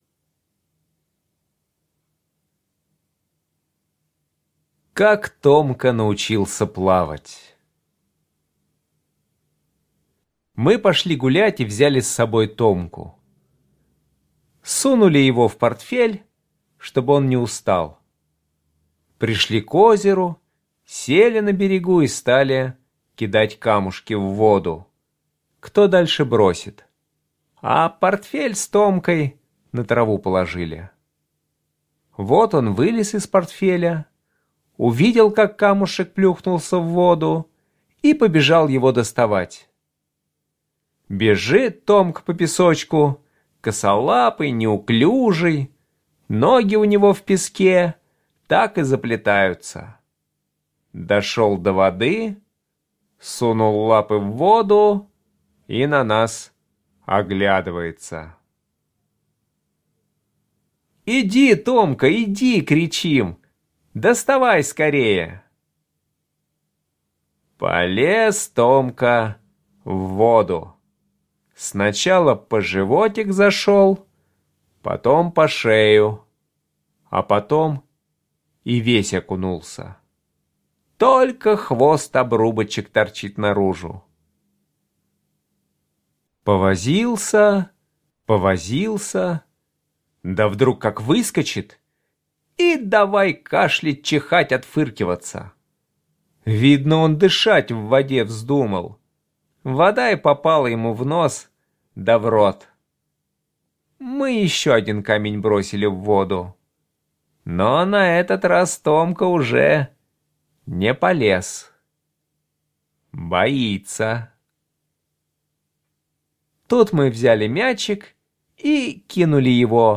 Аудиорассказ «Как Томка научился плавать»